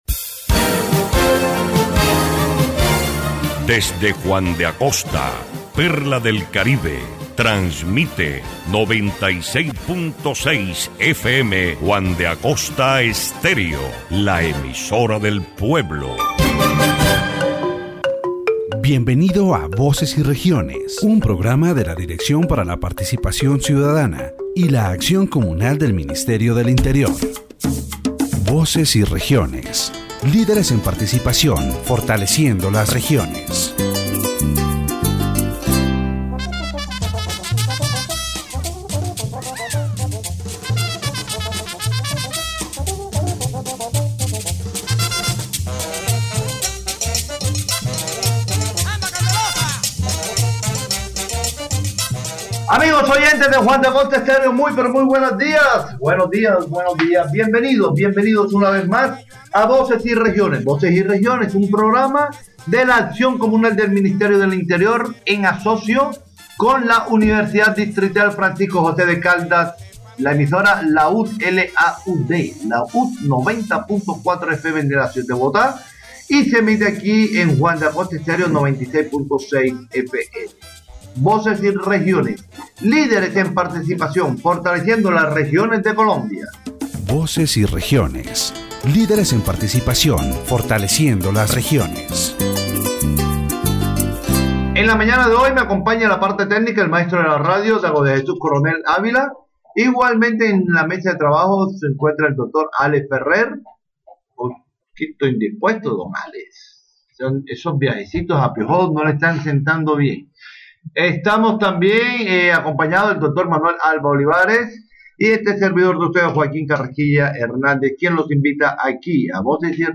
The Voces y Regiones program, broadcast from Juan de Acosta, Atlántico, discusses the political participation of women in Colombia. The conversation highlights the challenges women face in achieving equal representation, showing that while their numbers in Congress and mayoralties have increased, their presence remains marginal compared to men.